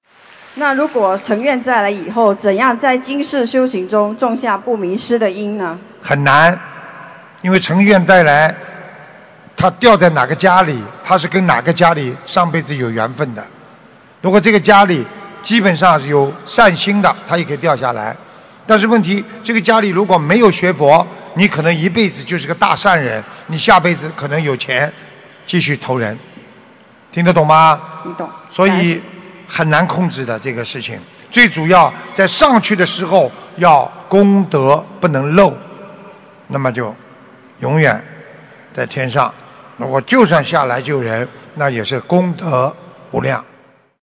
怎样才能在乘愿再来时不在人间迷失┃弟子提问 师父回答 - 2017 - 心如菩提 - Powered by Discuz!